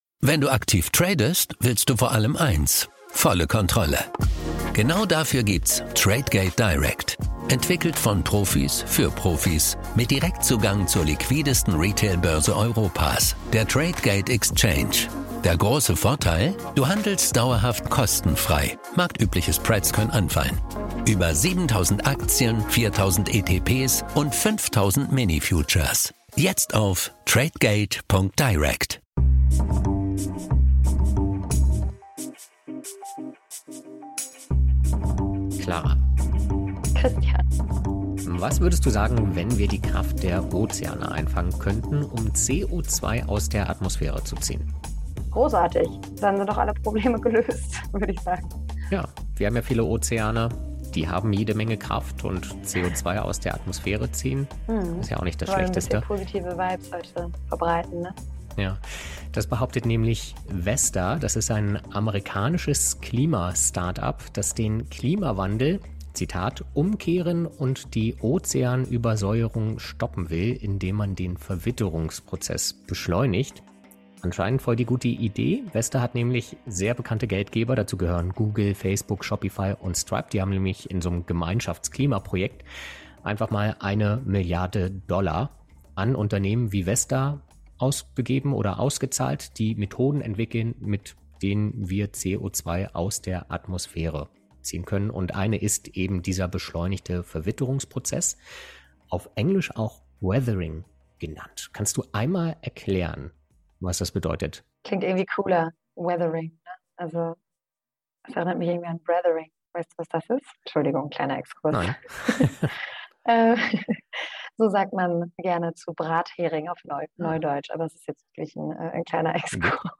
Dann bewertet das "Klima-Labor" bei Apple Podcasts oder Spotify Das Interview als Text?